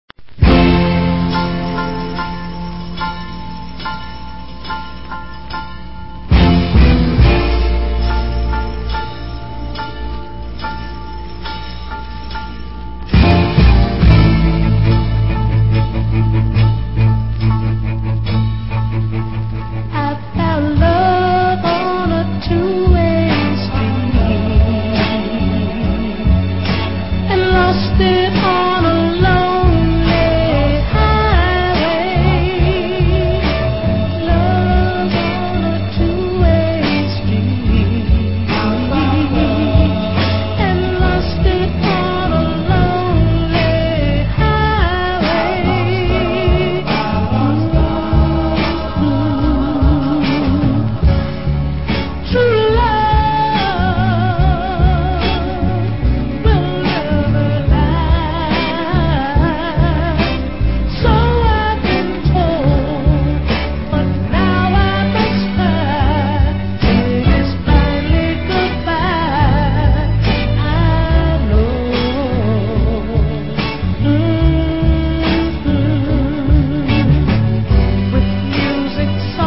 Excellent mid-tempo Crossover dancer